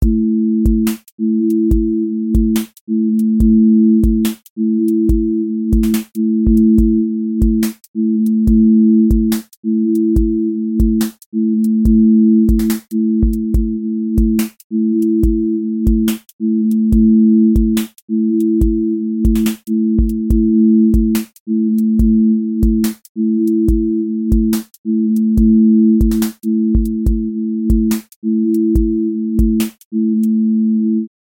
QA Listening Test drill Template: drill_glide
Drill glide tension with sliding low end